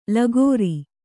♪ lagōri